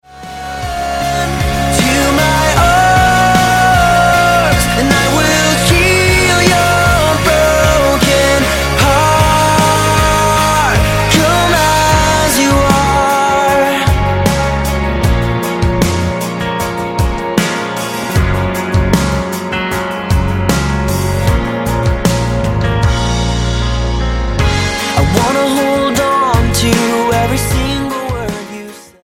pop rock band
Style: Rock